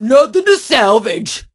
pam_kill_vo_02.ogg